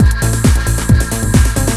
TECHNO125BPM 13.wav